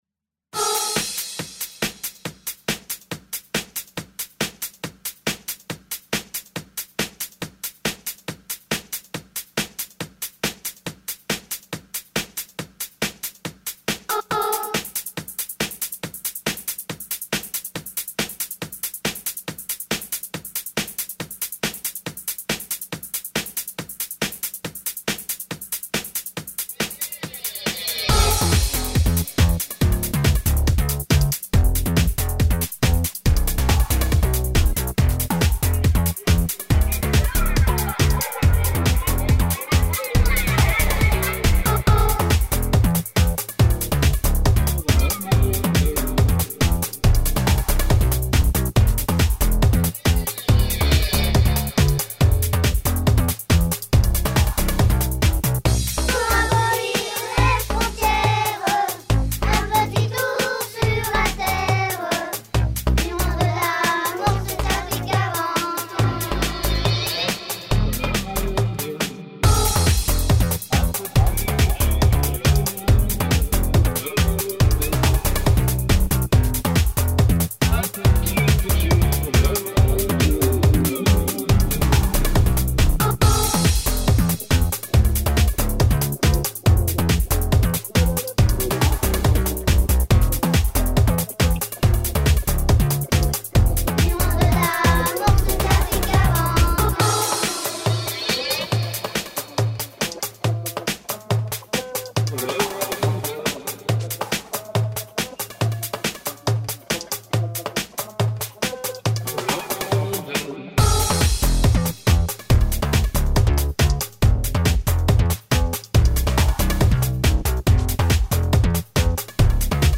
Version DANCE